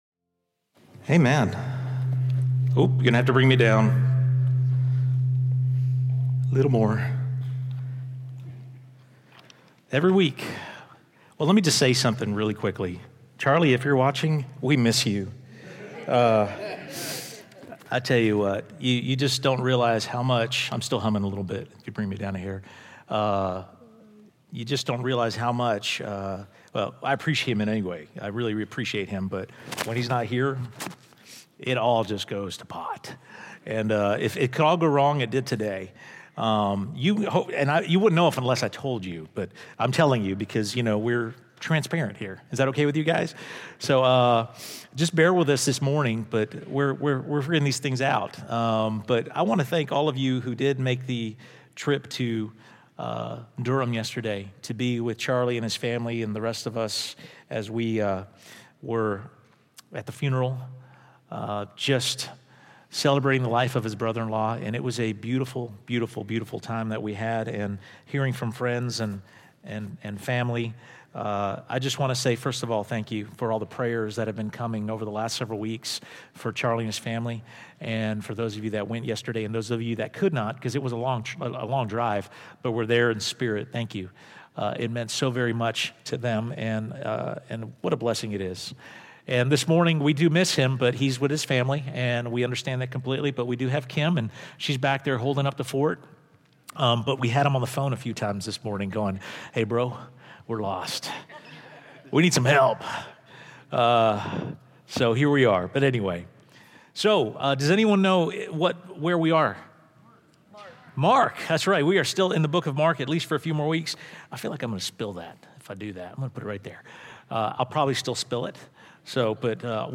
Welcome to episode 11 of our expository series "The Book of Mark."